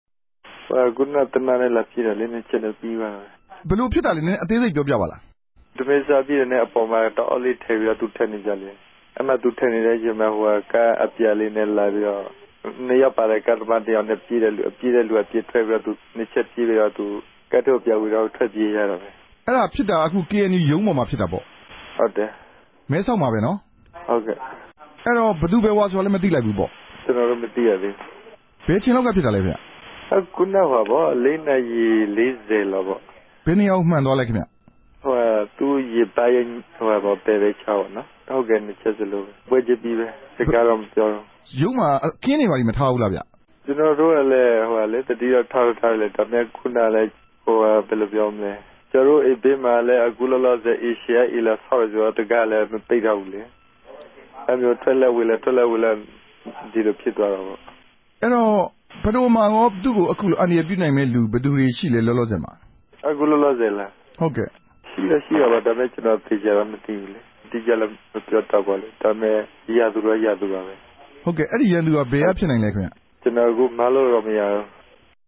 ဖဒိုမန်းရြာနဲႛ ထိတြေႚဆက်ဆံခဲ့ဖူးသူတေကြတော့ ဖဒိုဟာ ္ဘငိမ်းခဵမ်းရေးကို ခဵစ်ူမတိံိုးသူတဦးဆိုတာ ကောင်းကောင်းဋ္ဌကီးသဘောပေၝက်ုကပၝတယ်။ မဲဆောက်္ဘမိြႚမြာရြိတဲ့ အခင်းူဖစ်ပြားရာ KNU ႟ုံးအဖြဲႚသား တဦးကို RFA က ဆက်သြယ် မေးူမန်းထားတာကို နားဆငိံိုင်ပၝတယ်။